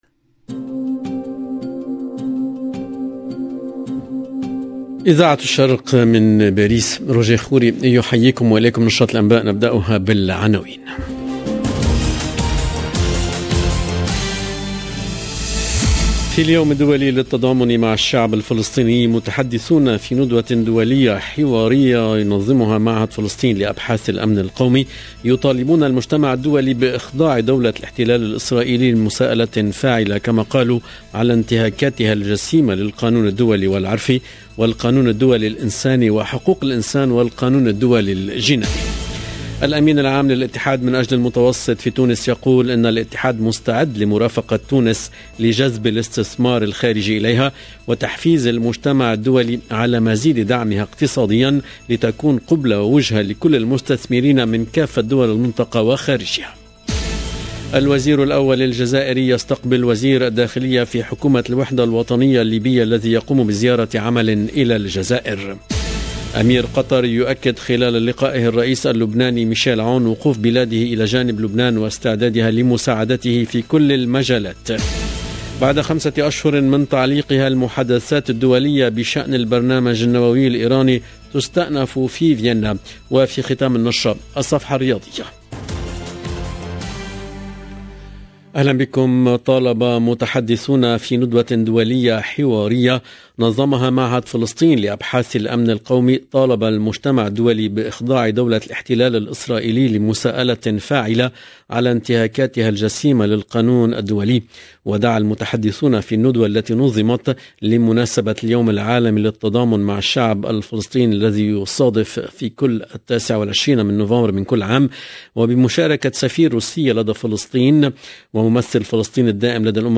LE JOURNAL DE MIDI 30 EN LANGUE ARABE DU 29/11/21